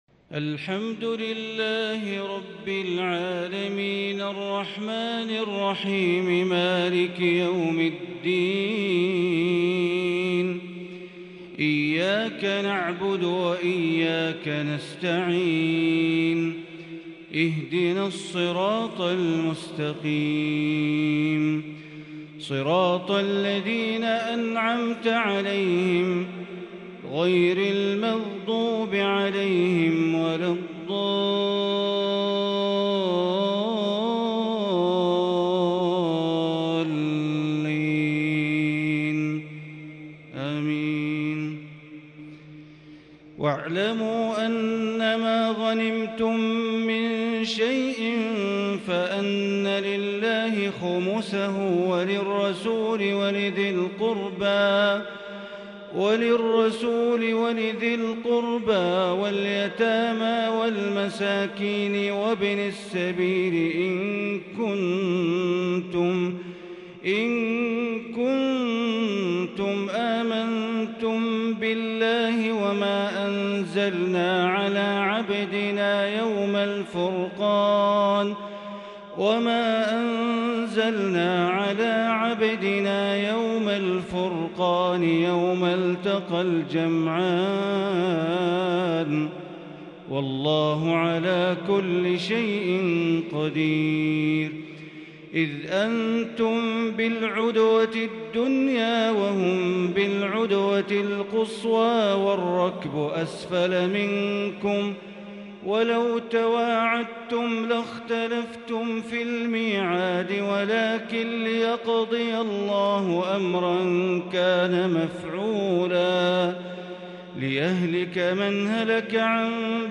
تراويح ليلة 13 رمضان بتحبير بياتي خاشع من الأنفال(40-75)واستفتاح بالكرد العذب لسورة التوبة (1-27) > تراويح ١٤٤٢ > التراويح - تلاوات بندر بليلة